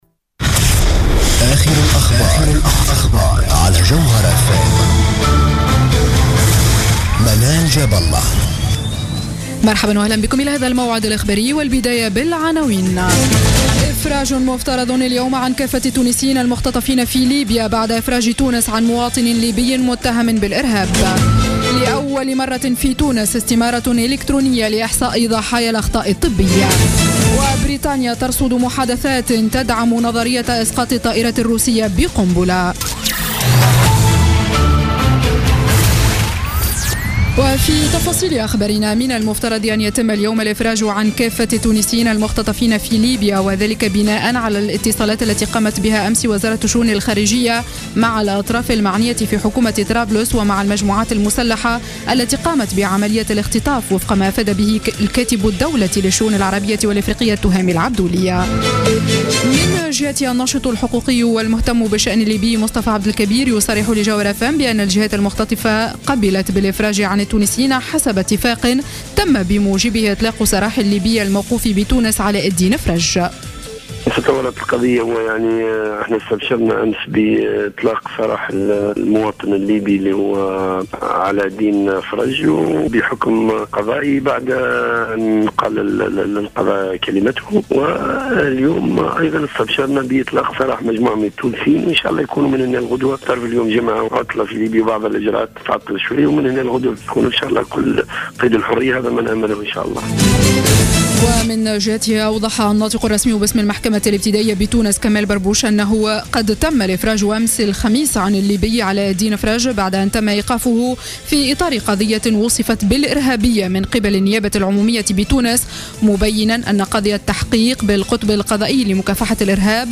نشرة أخبار السابعة مساء ليوم الجمعة 06 نوفمبر 2015